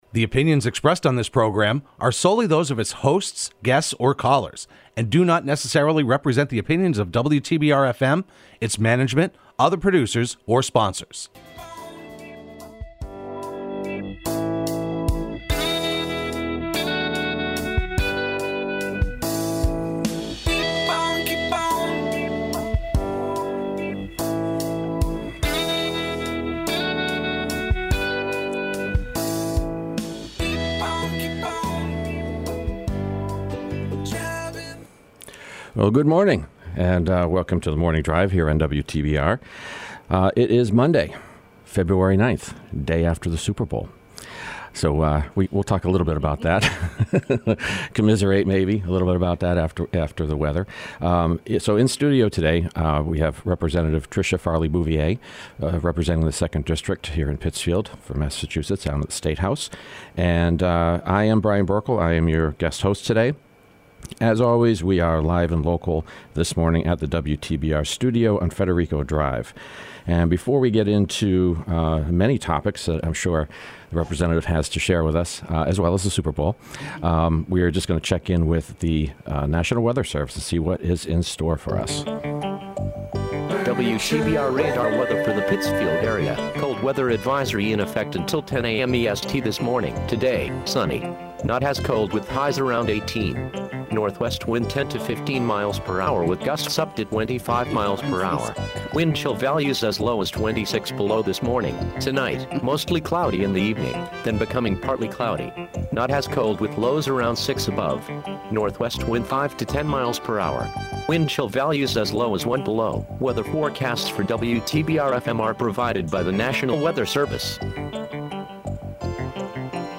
spends the hour talking with State Representative Tricia Farley-Bouvier